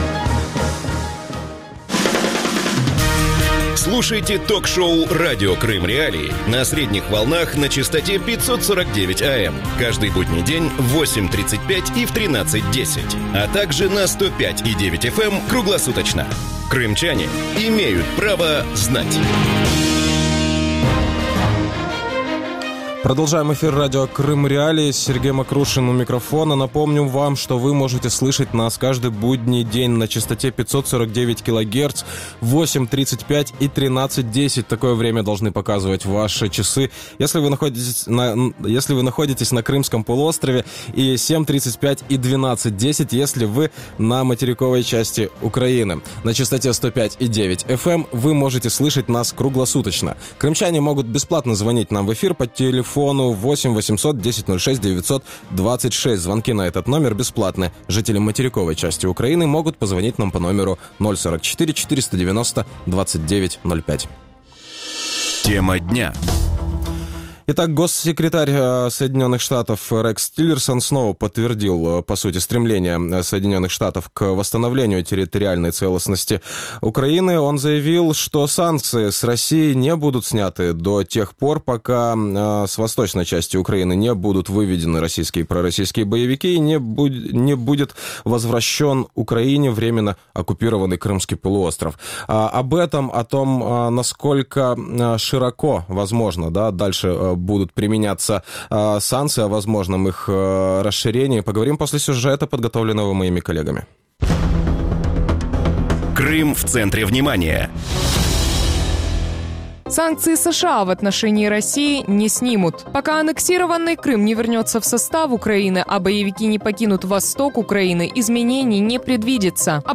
Радио Крым.Реалии эфире 24 часа в сутки, 7 дней в неделю.